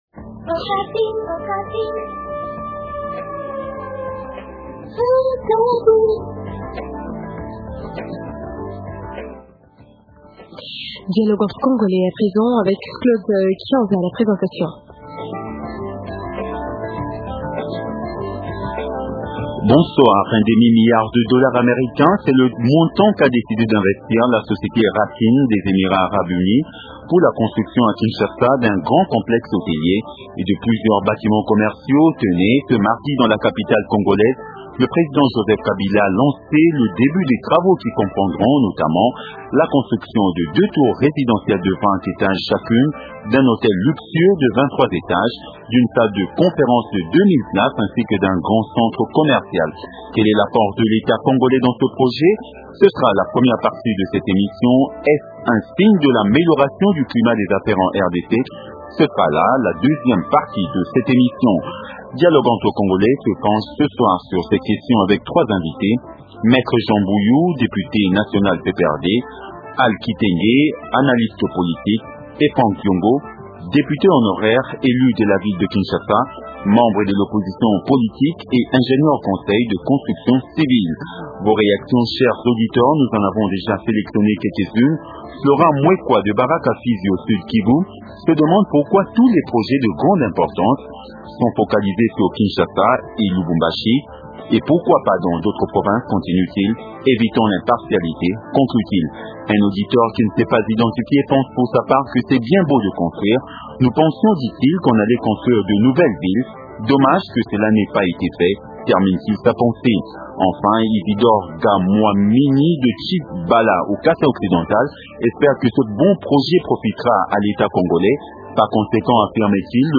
-Est-ce un signe de l’amélioration du climat des affaires en Rdc ? Invités : -Pierre Lumbi, Ministre des infrastructures.